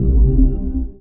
booglerbass.wav